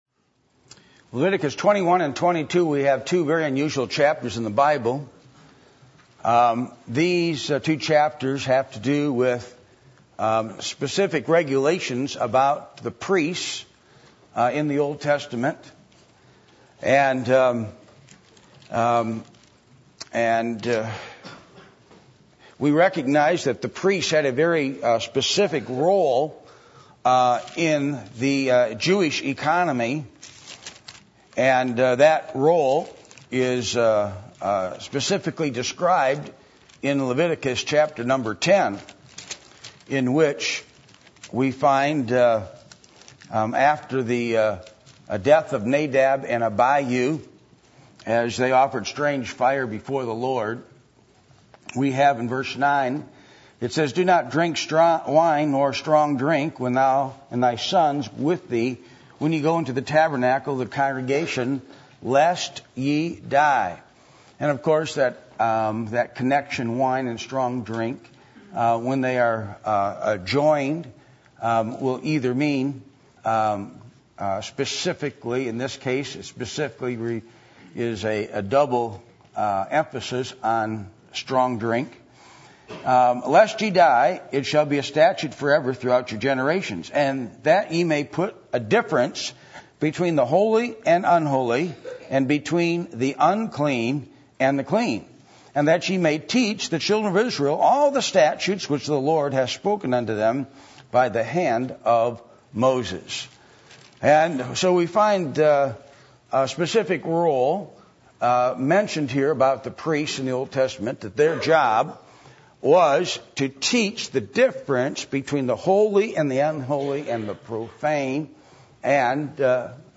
Passage: Leviticus 21:1-22:33 Service Type: Sunday Evening